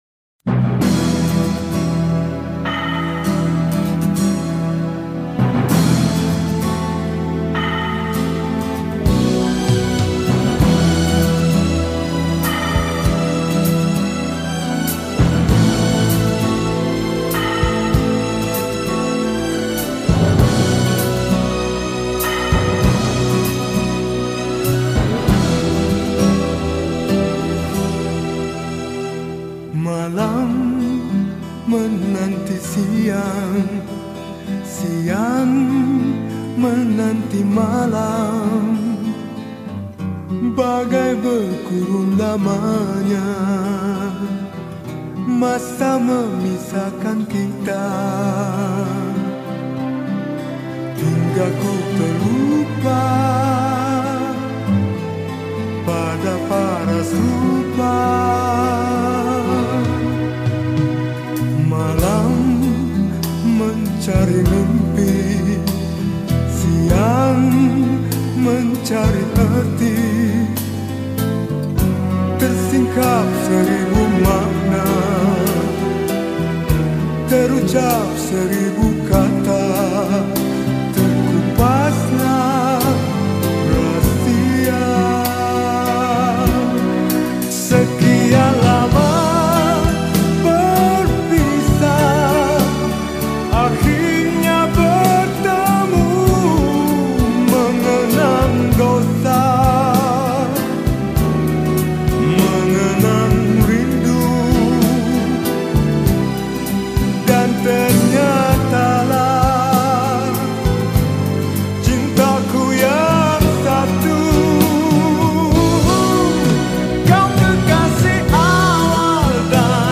Malay Song